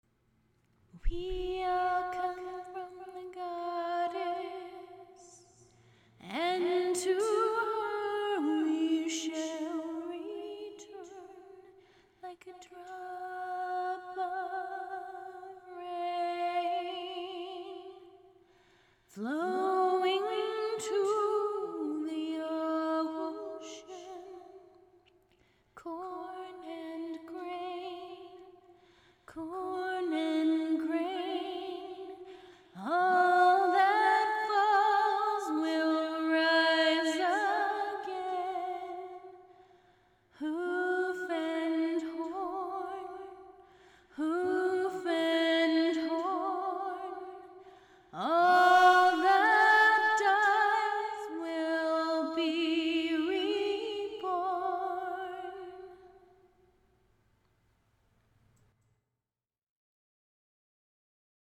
Posted in Vocals Comments Off on